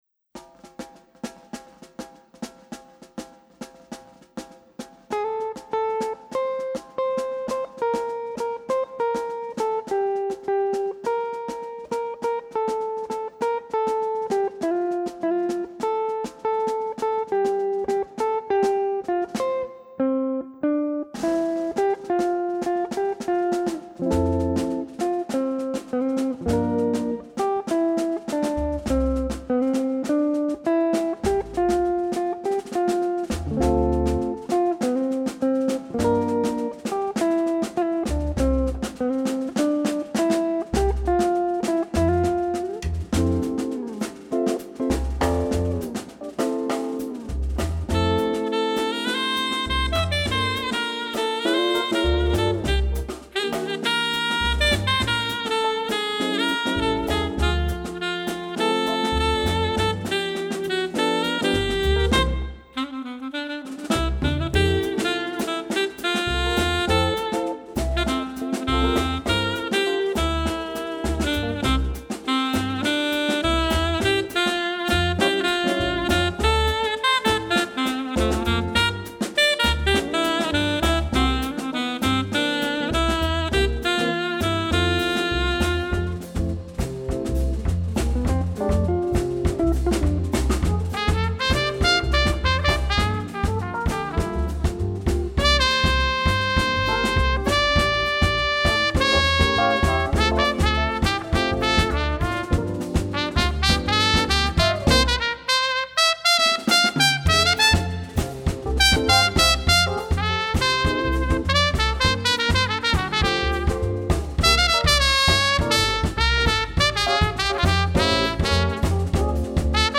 guitar
alto/soprano sax
trumpet